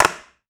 Skateboard Intense Landing.wav